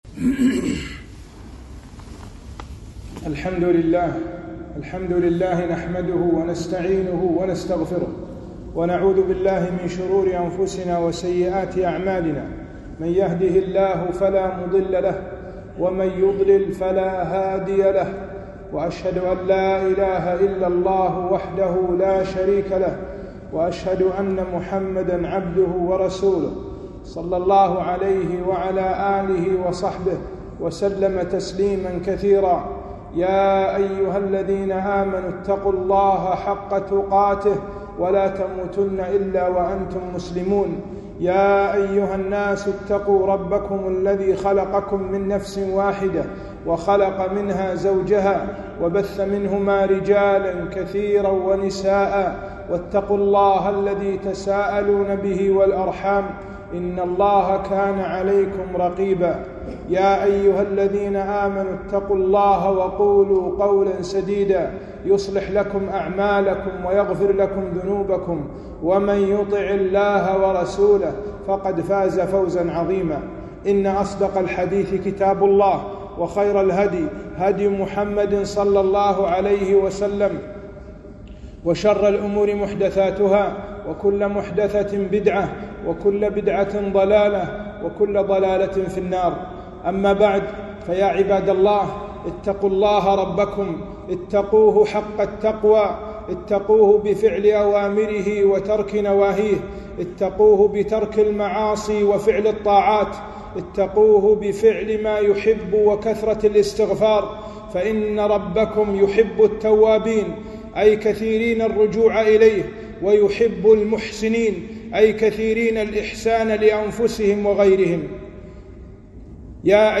خطبة - أنواع الغيث